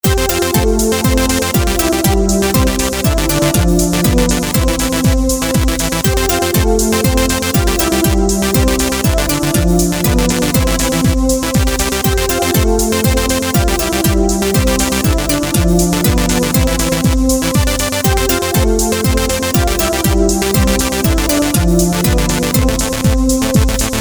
Написание музыки